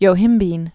(yō-hĭmbēn)